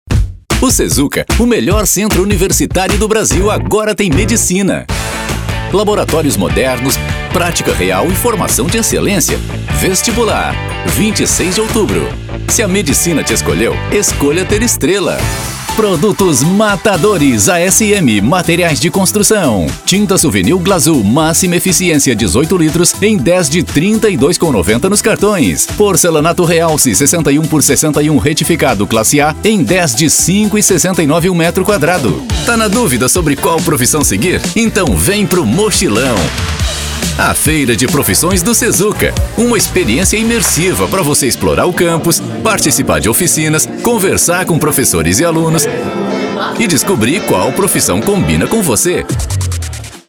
Mi voz es profunda, amigable, natural y conversacional.
Trabajo desde mi propio estudio profesionalmente equipado y con tratamiento acústico.